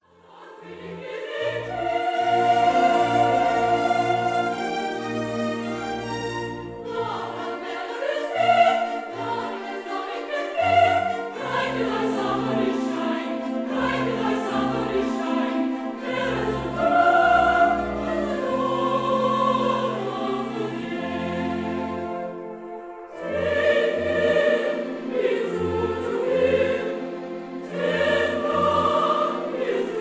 baritone
tenor
bass
soprano
contralto